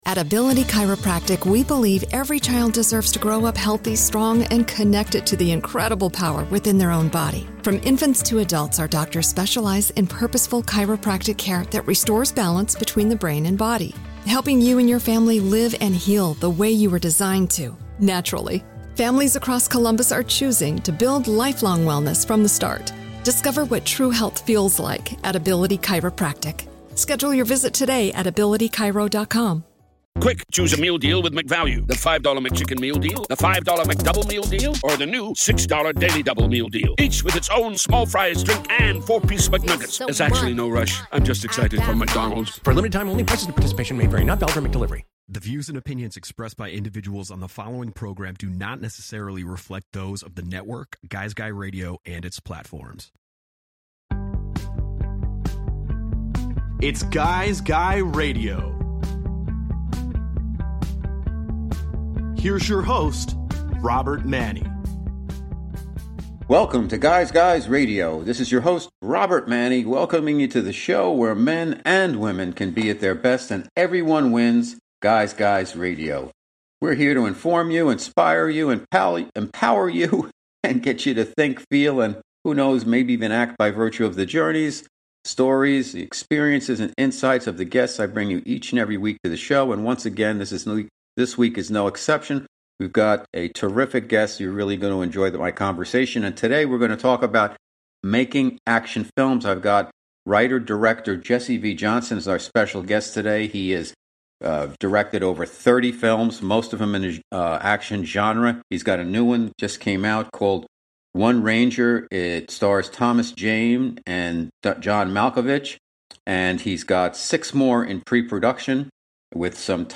Guy’s Guy Radio features interviews and in-depth conversations with thought leaders across the worlds of relationships and modern masculinity, spirituality, health, wellness and diet, business, and much more.